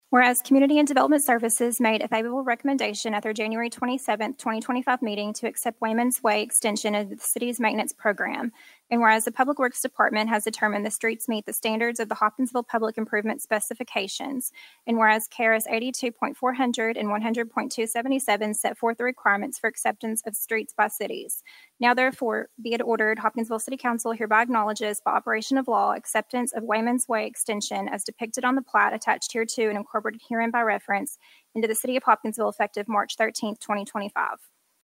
City Clerk Brittany Byrum presented the municipal ordinance for accepting the Weyman’s Way street extension to the council.